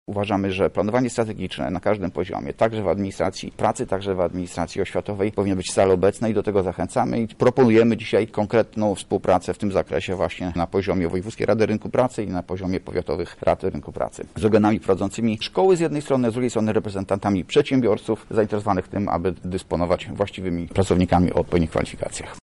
Pozycja naszego regionu jest nie do końca satysfakcjonująca, a chcielibyśmy szybciej i lepiej konkurować w tym świecie, który jest strasznie niekonkurencyjny – mówi dyrektor Wojewódzkiego Urzędu Pracy w Lublinie Andrzej Pruszkowski: